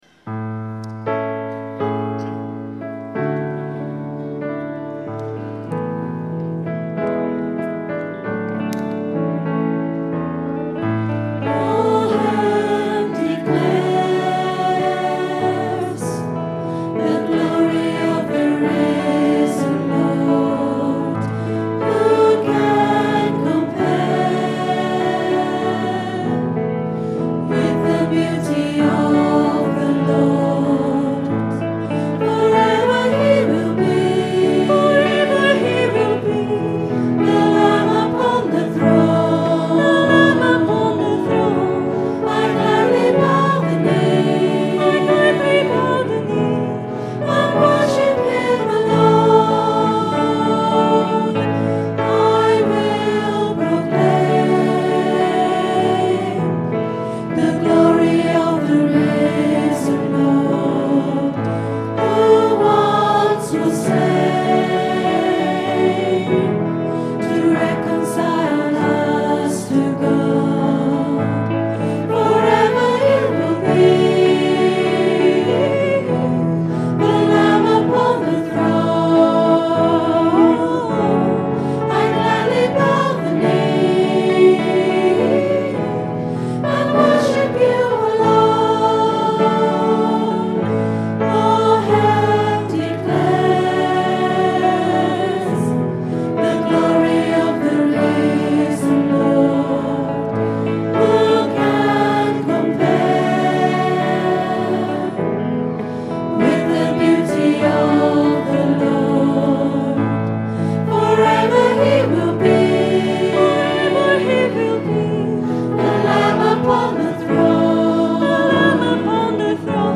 Recorded at Mass, 4th September 2011 on a Zoom H4 digital stereo recorder.